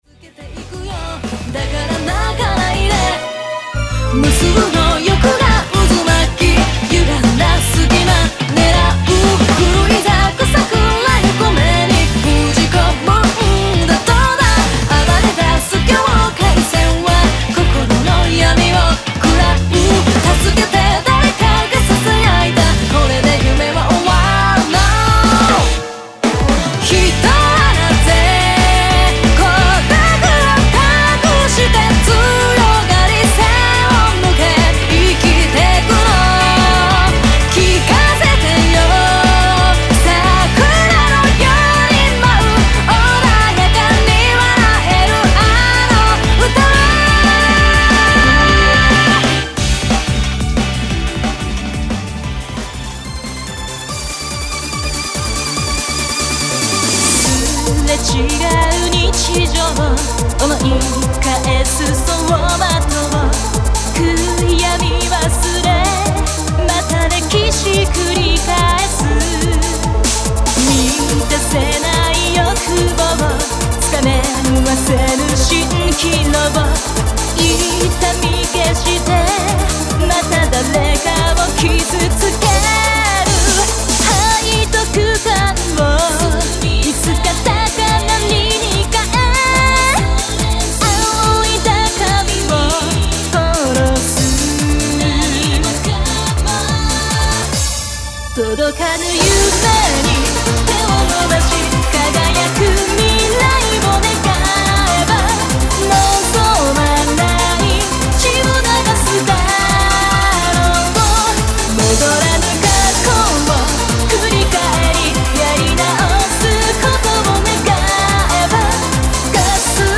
爽快＆リズミカルな音色が重なり合う――艶やかな旋律（メロディ）！！
春をイメージした爽快＆リズミカルなPOPSアレンジを中心にヴォーカル4曲を含む全8曲を収録。